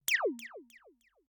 Kenney's Sound Pack/Digital Audio • Directory Lister
laser7.ogg